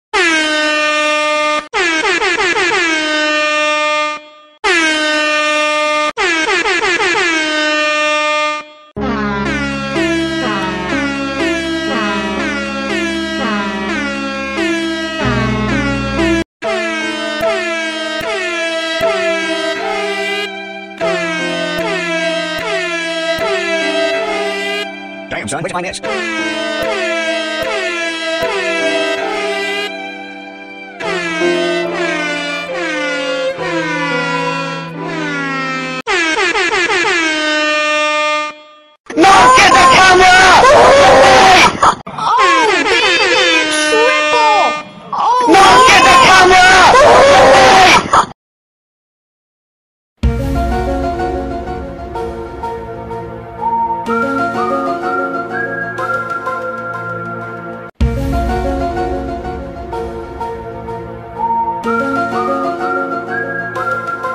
AIRHORNMLG.mp3